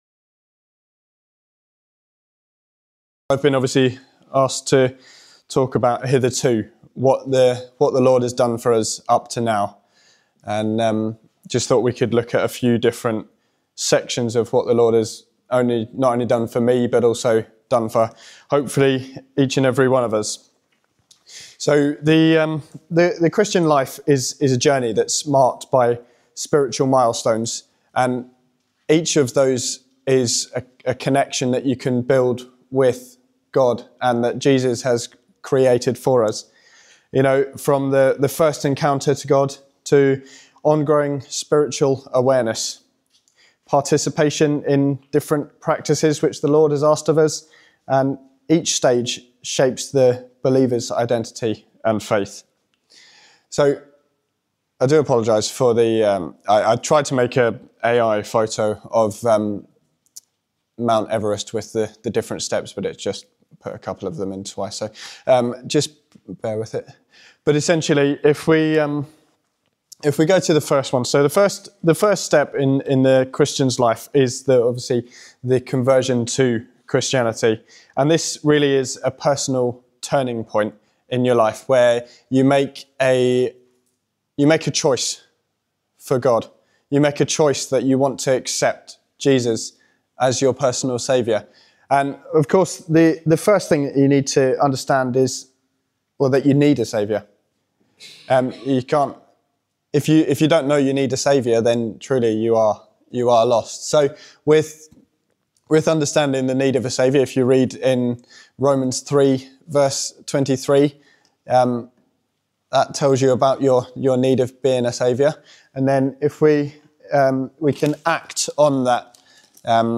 This talk from Refresh 2026 reminds us that God has faithfully supported and delivered His people, even providing salvation. Reflecting on His past help strengthens our confidence that He will continue to guide, protect, and save us in the future.